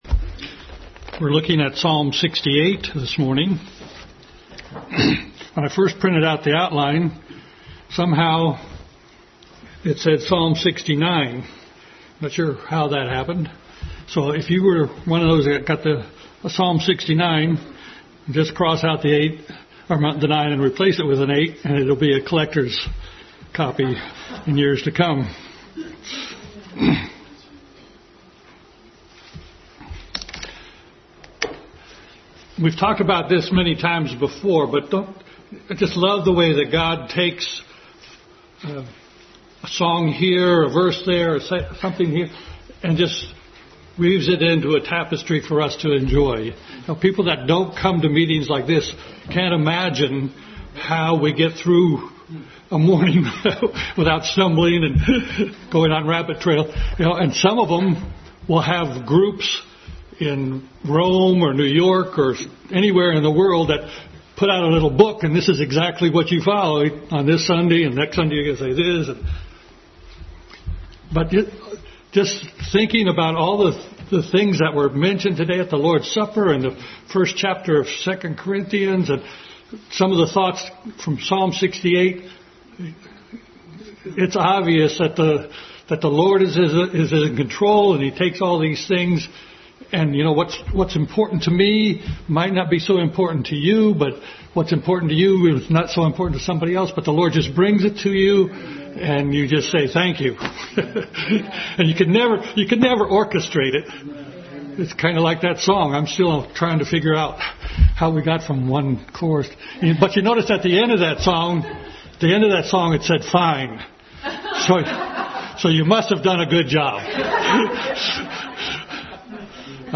The Coronation of the King Passage: Psalm 68 Service Type: Family Bible Hour Family Bible Hour message.